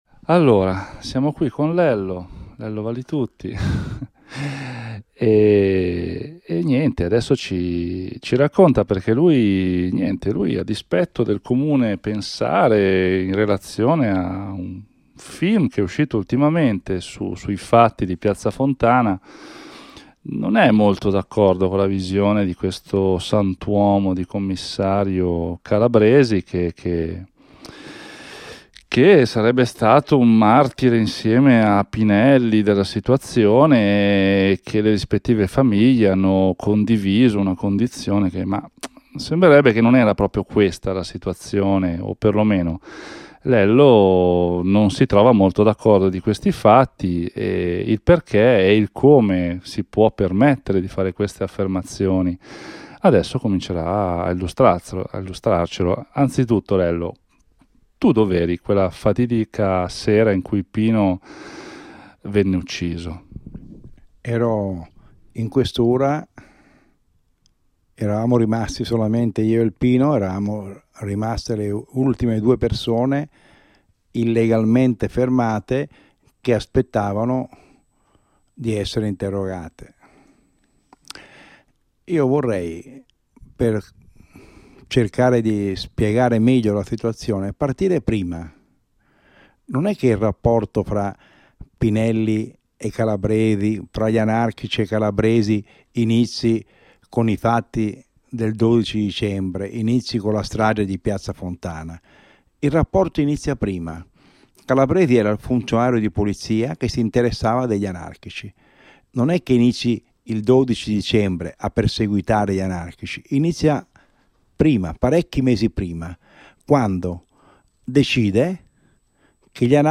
Insuscettibile di ravvedimento. Una conversazione